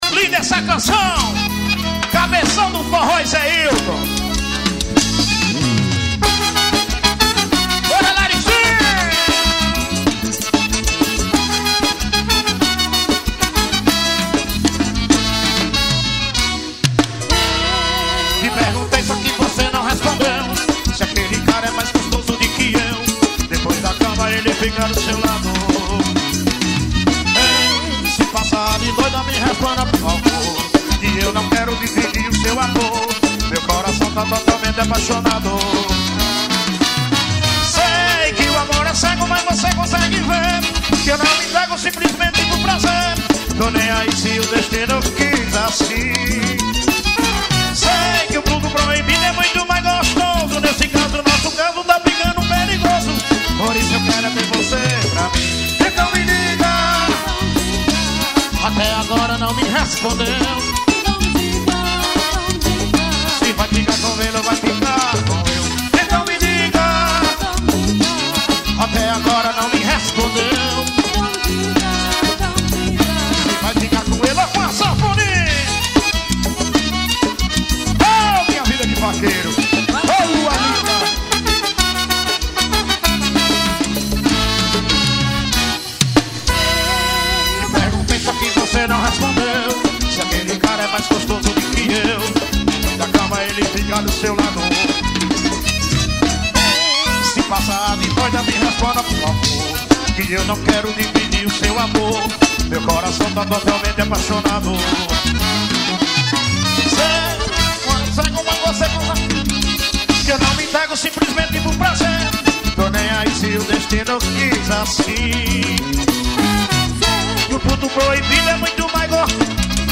Show ao vivo.